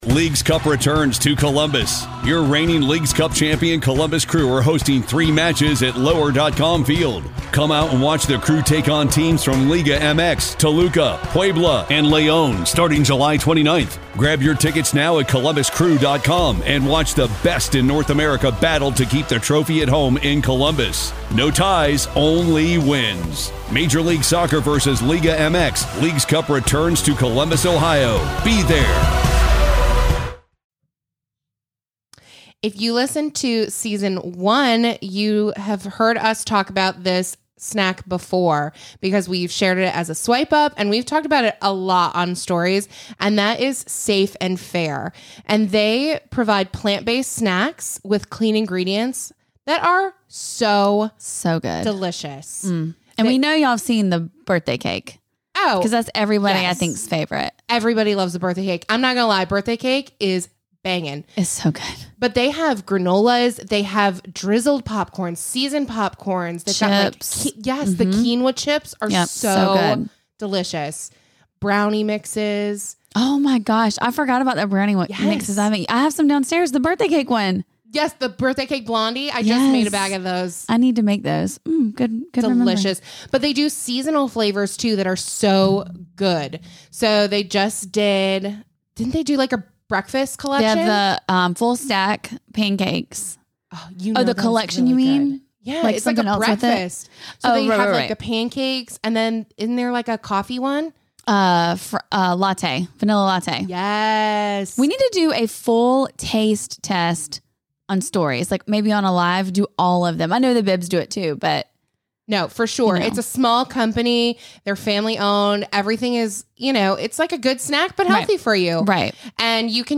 The girls talk about a cosmetic nightmare, show a local girl some love and break down yet another BIB on BIB crime.